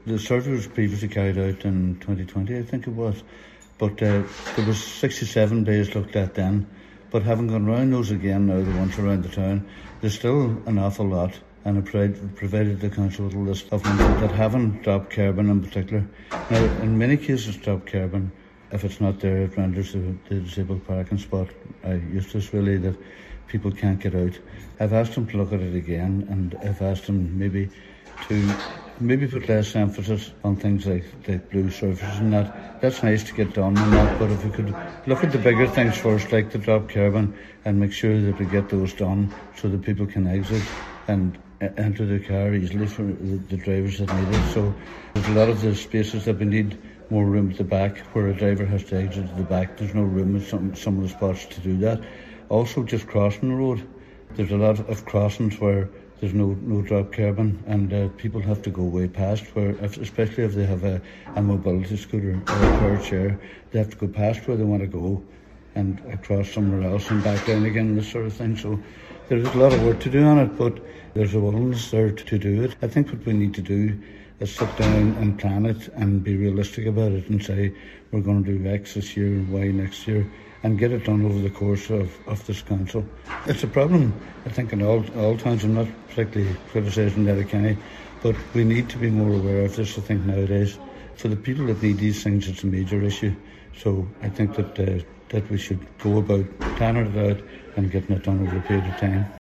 Councillor Jimmy Kavanagh believes the measures could be implemented on a phased basis to ease costs with a focus initially on dropped kerbing: